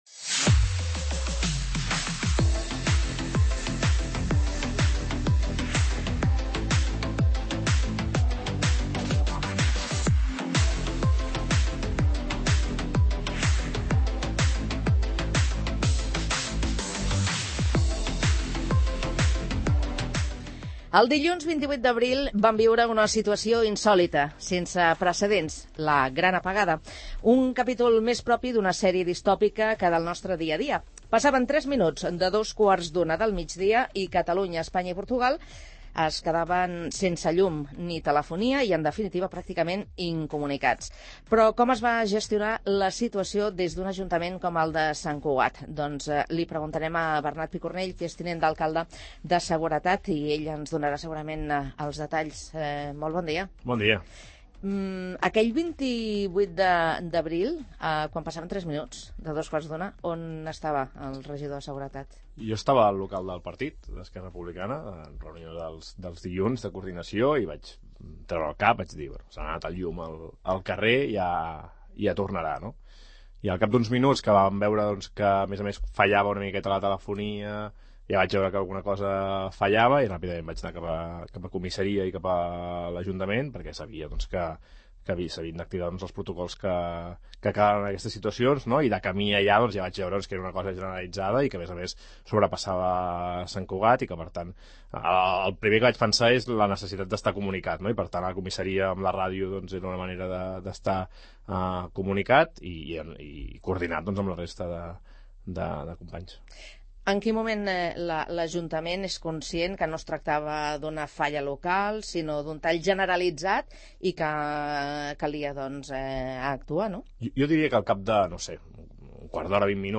El tinent d'alcaldia de Seguretat, Bernat Picornell, a R�dio Sant Cugat / Foto: Cugat M�dia
En una entrevista al magaz�n 'Faves comptades', el tinent d'alcaldia de Seguretat, Bernat Picornell, ha destacat la coordinaci� entre serveis, la transpar�ncia en la comunicaci� i la resposta exemplar de la ciutadania davant d'una situaci� inesperada.